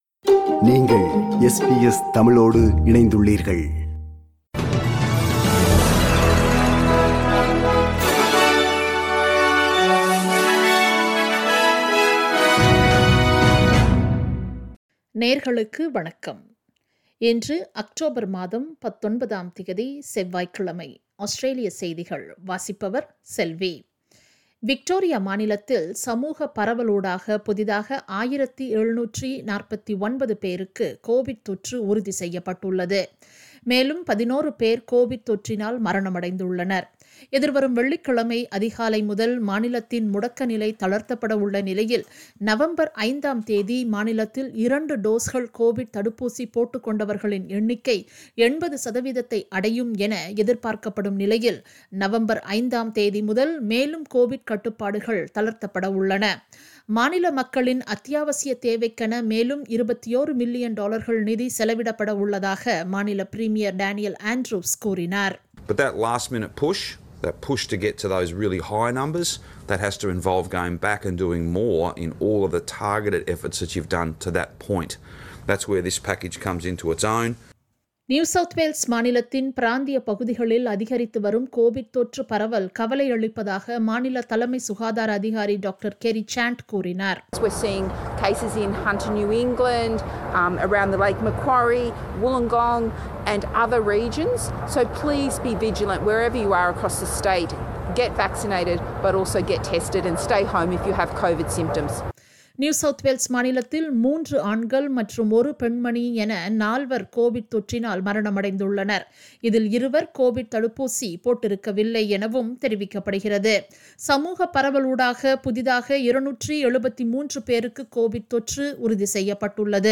Australian news bulletin for Tuesday 19 October 2021.